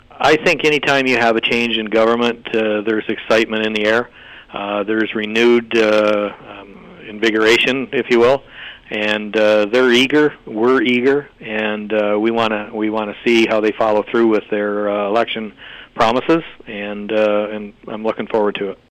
Robert-Quaiff-interview-Clip.mp3